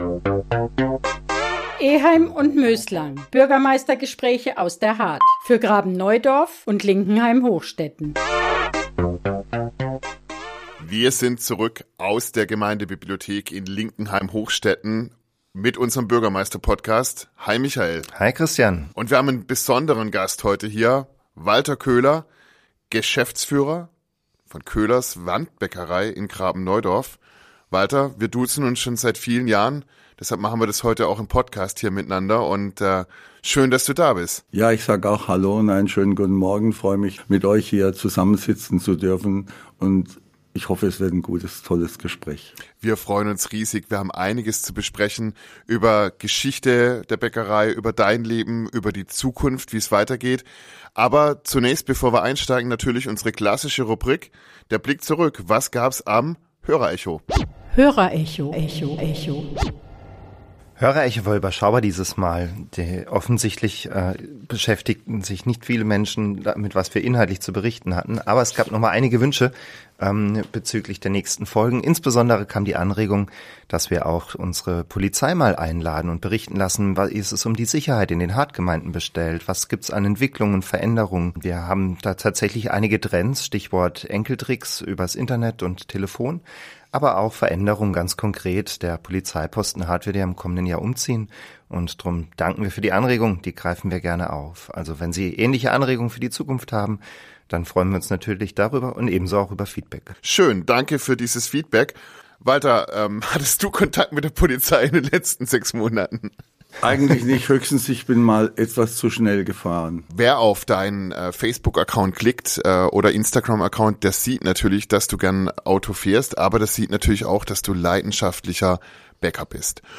Eheim & Möslang – Der Bürgermeister-Podcast Folge 8 ~ Eheim & Möslang - Bürgermeistergespräche aus der Hardt für Graben-Neudorf und Linkenheim-Hochstetten Podcast